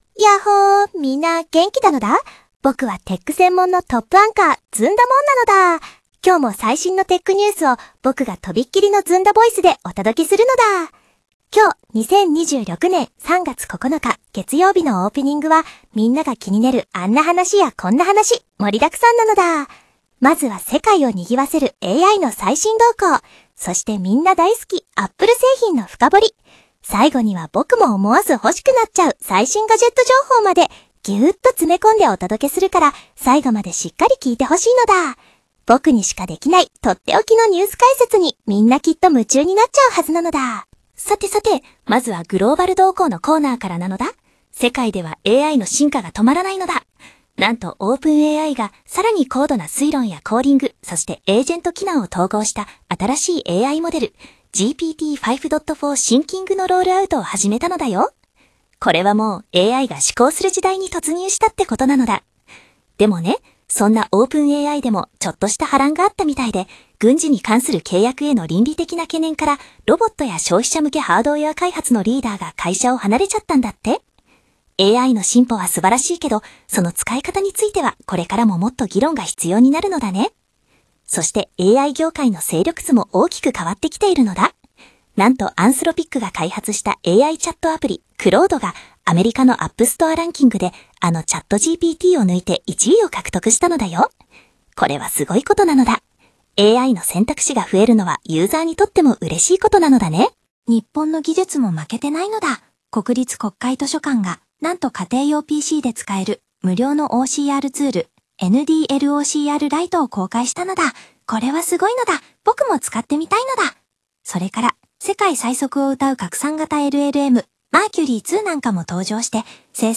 ずんだもん風テックニュース 2026年03月09日00時17分